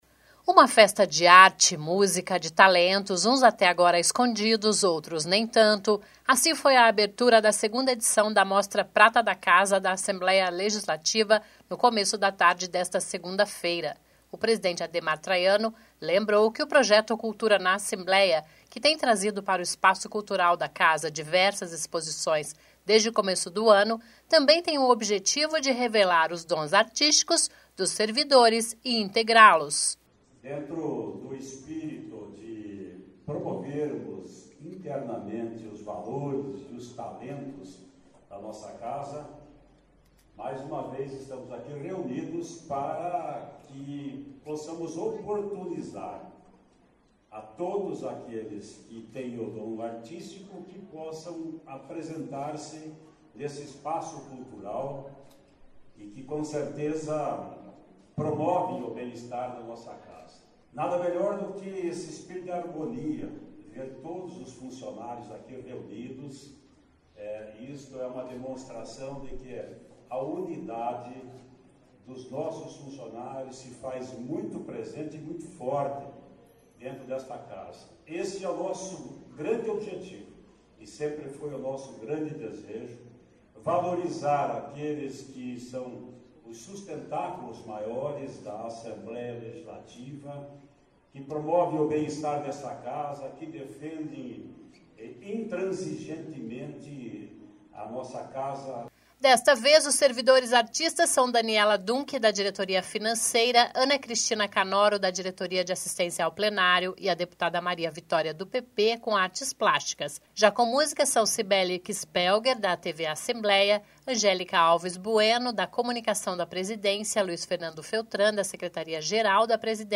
(Sonora)
(Sobe som)